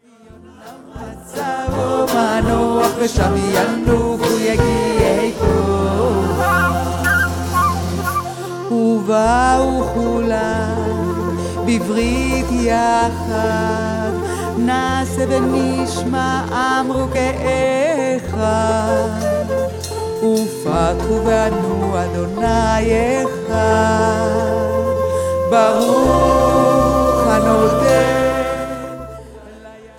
In her warm, deep voice
Folk